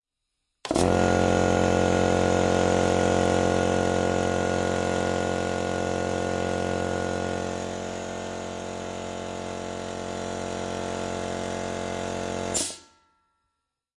机器嗡嗡声